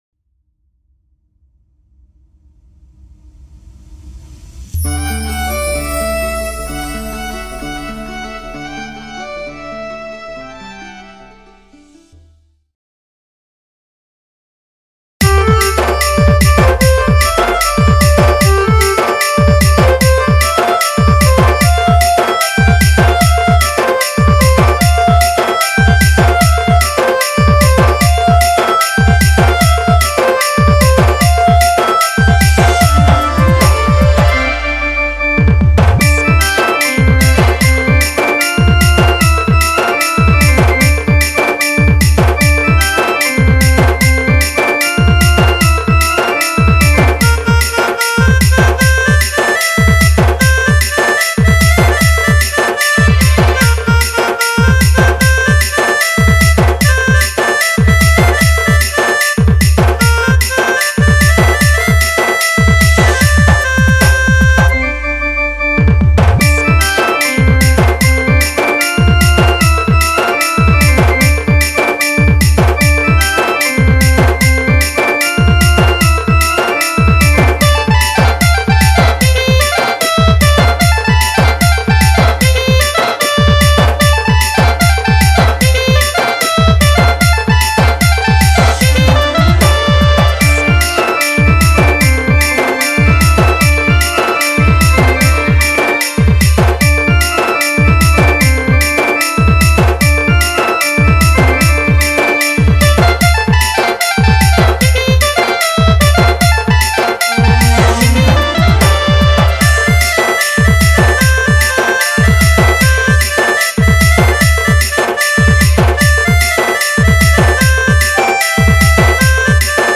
Instrumental Music And Rhythm Track Songs Download